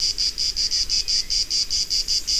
c.) eine einzelne Cigale de l'Orne, ebenfalls aufgenommen in Kroatien